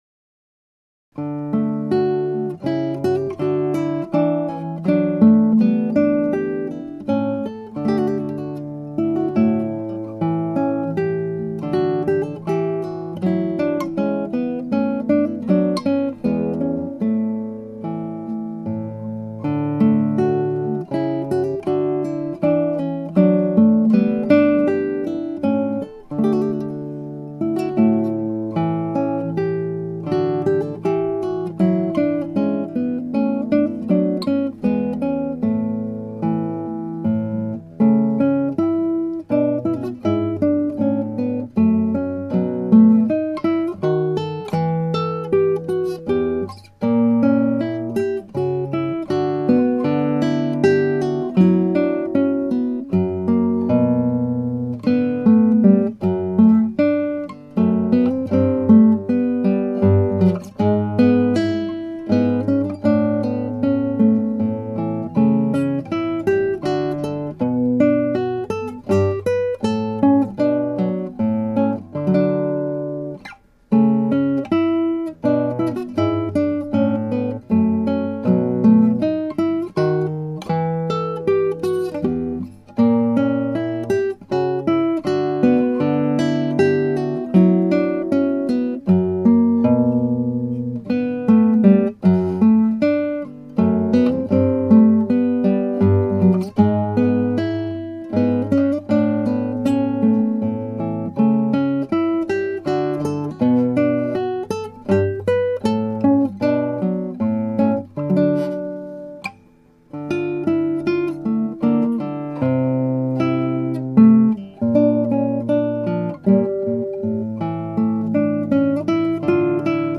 ギター演奏ライブラリ
(アマチュアのクラシックギター演奏です [Guitar amatuer play] )
原調はト長調ですがギター版はニ長調です。
もう少し軽やかに早いテンポで弾きたかったのですが指がもつれてしまうのでこのテンポになりました。